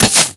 Crossbow.ogg